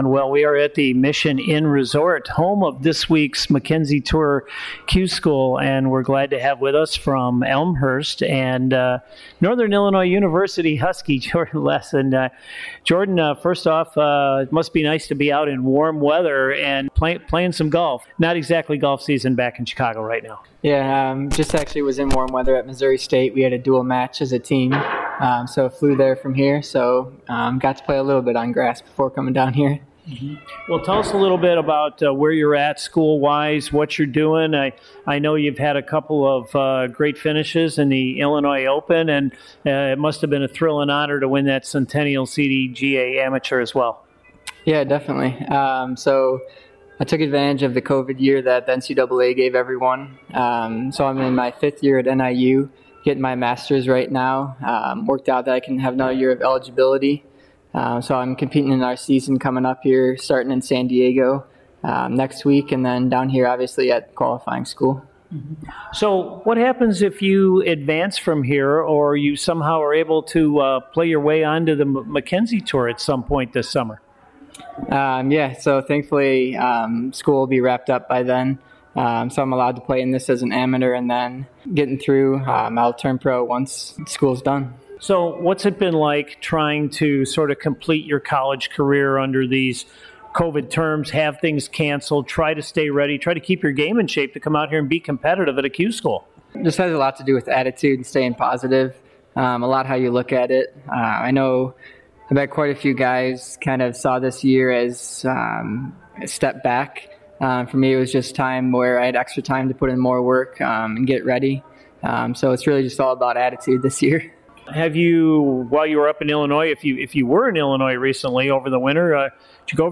(Reporting from Howey in the Hills-Florida and the Mission Inn Resort)
interview